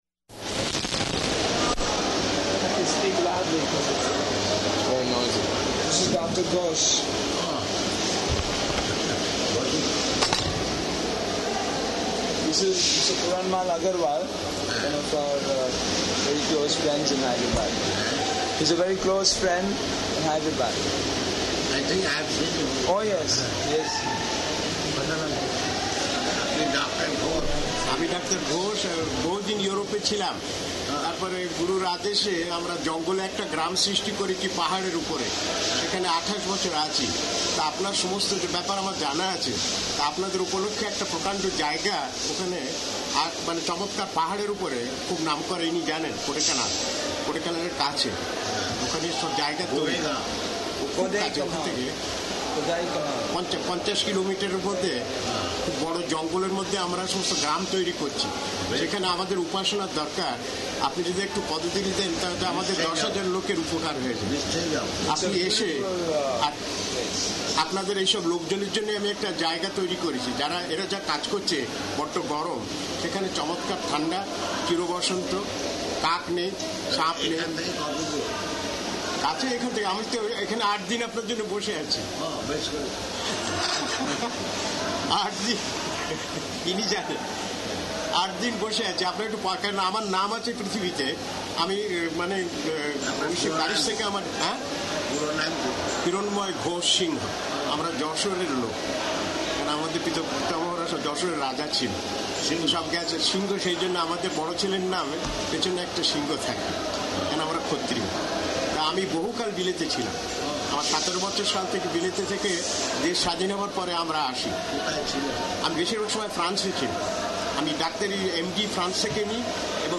Room Conversation
-- Type: Conversation Dated: August 17th 1976 Location: Hyderabad Audio file
[much background noise]